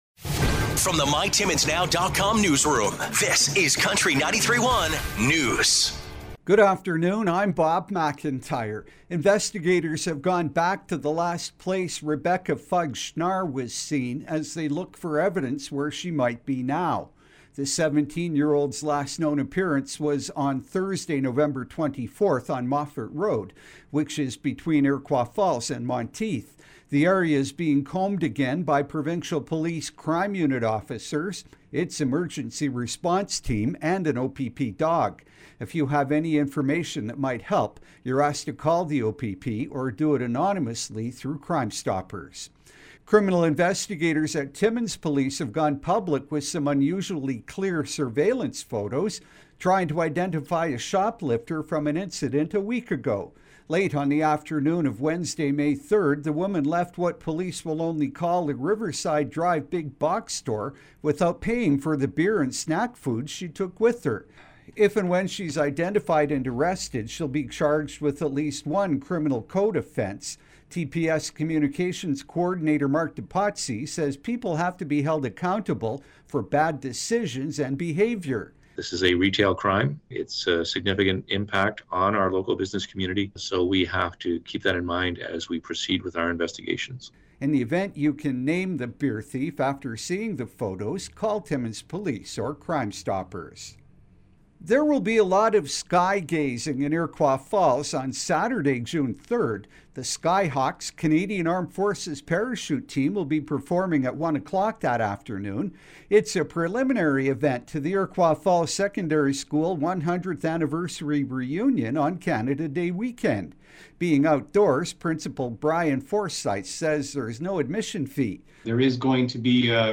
5:00pm Country 93.1 News – Wed., May 10, 2023